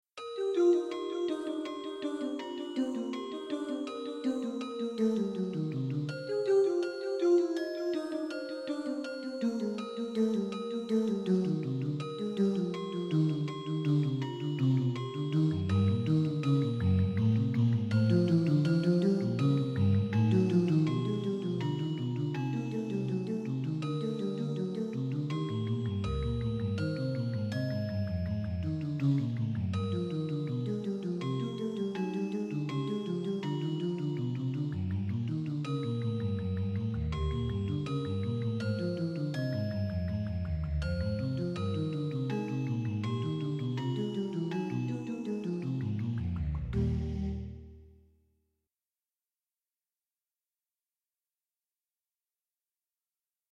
Most of these pieces I performed myself in public concerts.
The sound quality is slightly better on the CD.